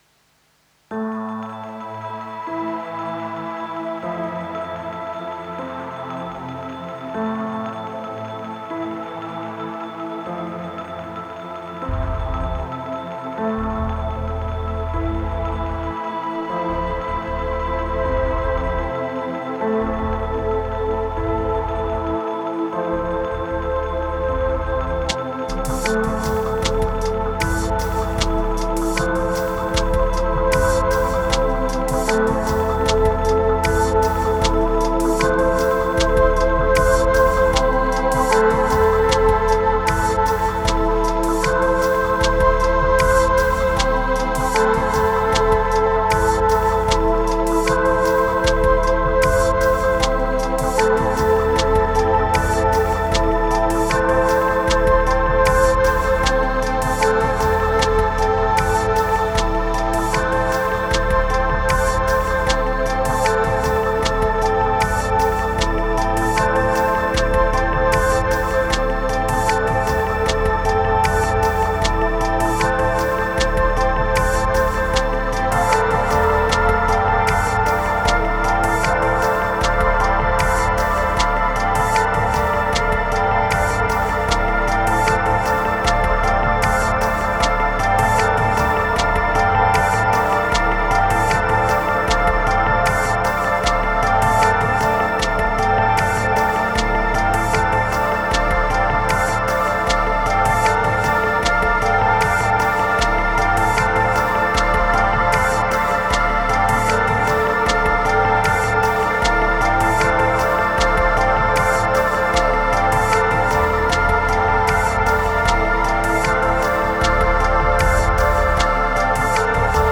Felt sad but warm.